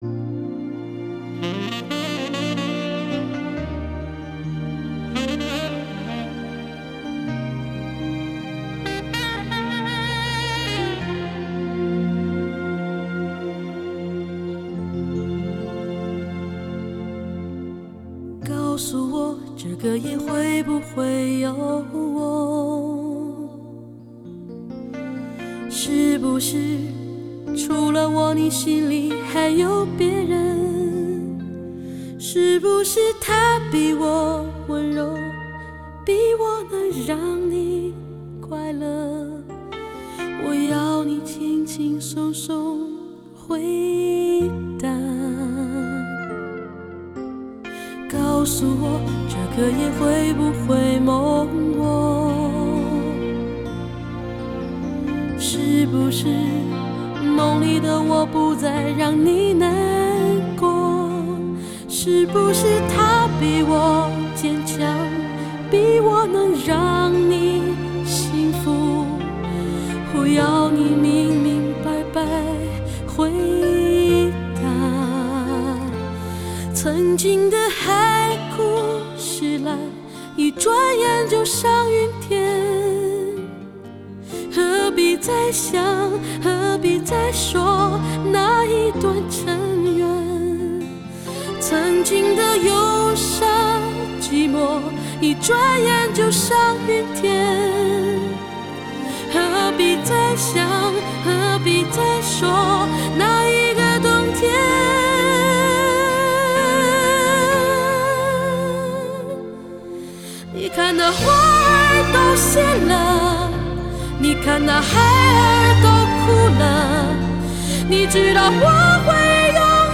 华语中文